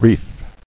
[reef]